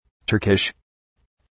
Προφορά
{‘tɜ:rkıʃ}